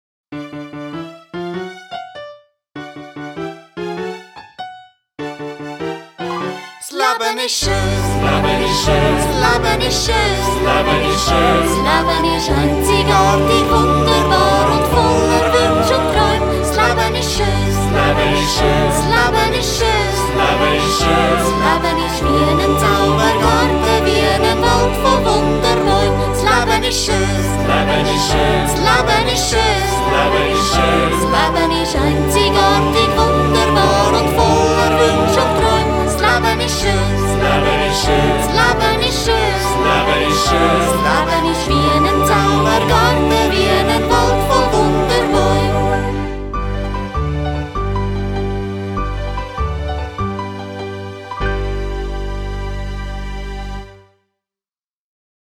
MärchenMusical